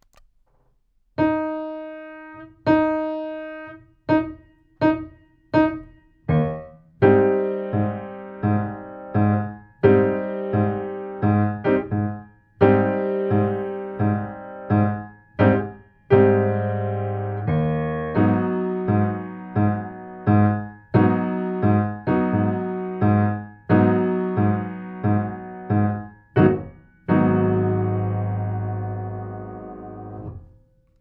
カラオケ音源1inE♭